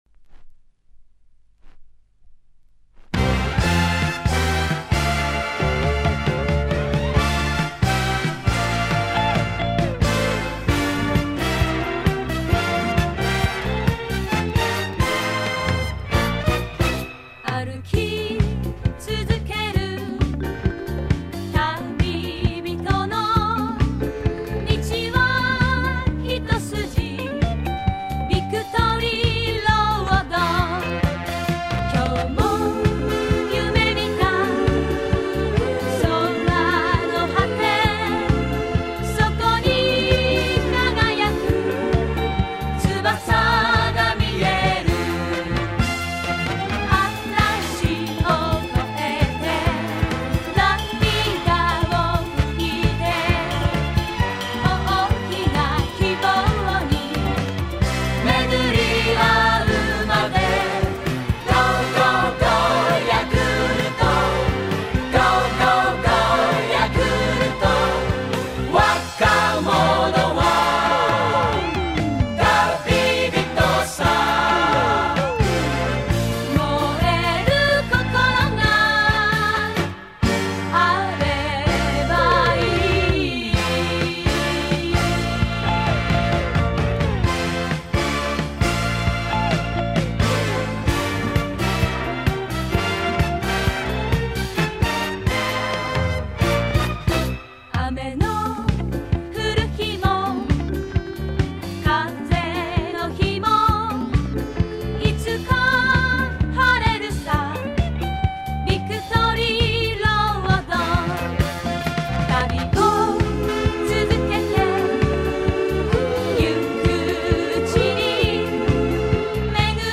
軍歌調のメロディーが多かった当時の応援歌に、「ポップ」で都会調のメロディー。